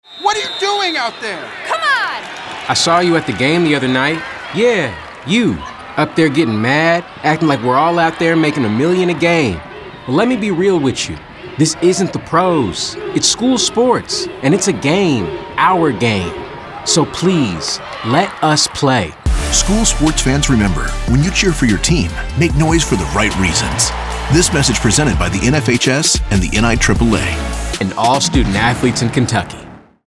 24-25 Radio – Public Service Announcements